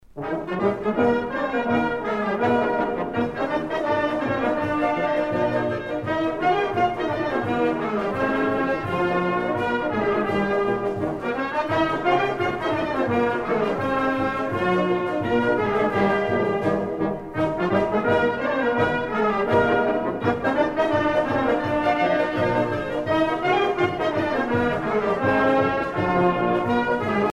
danse : horo (Bulgarie)